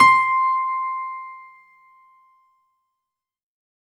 C5  DANCE -L.wav